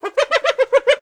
c_hyena_atk1.wav